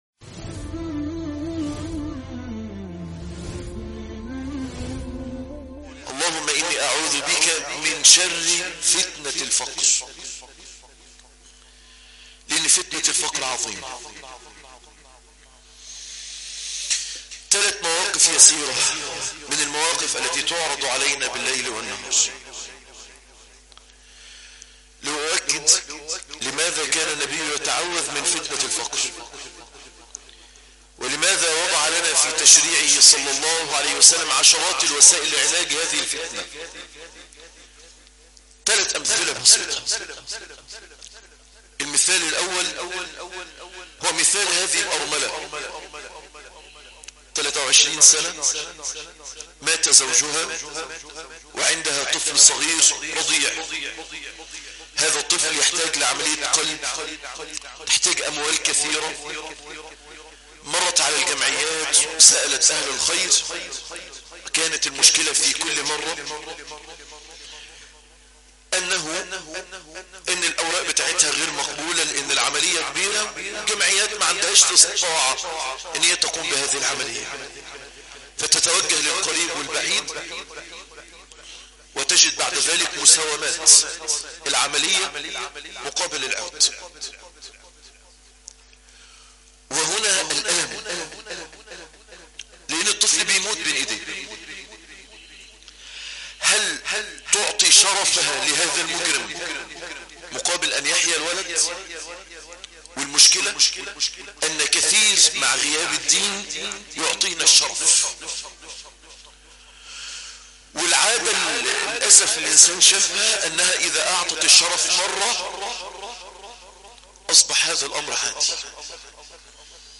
10- كيف عالج الإسلام قضية الفقر - خطبة الجمعة - مسجد نور الإسلام